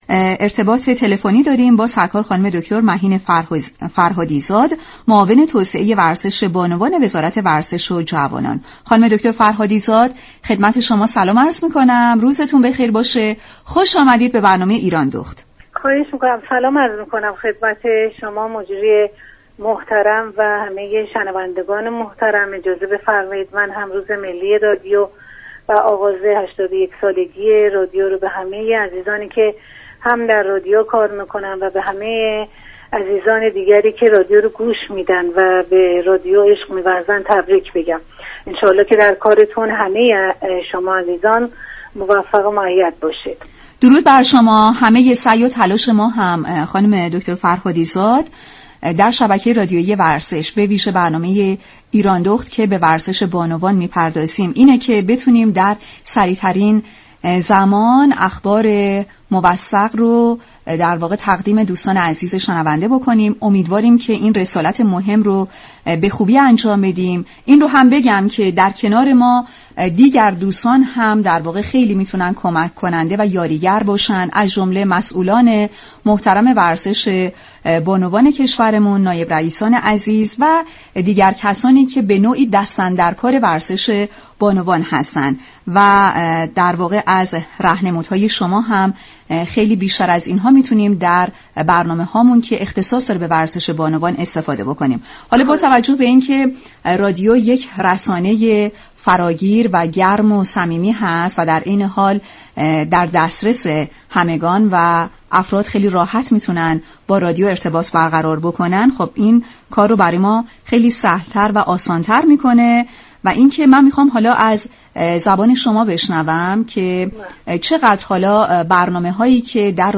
به گزارش رادیو ورزش؛ دكتر مهین فرهادی زاد، معاون توسعه ورزش بانوان وزارت ورزش و جوانان در گفتگو با برنامه ایراندخت ضمن تشكر از همكاری خوب شبكه رادیویی ورزش در توسعه ورزش بانوان، مهمترین اتفاقات ورزشی در سال جاری را ارائه كرد. شما می توانید از طریق فایل صوتی پیوست شنونده این گفتگو باشید.